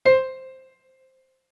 MIDI-Synthesizer/Project/Piano/52.ogg at 51c16a17ac42a0203ee77c8c68e83996ce3f6132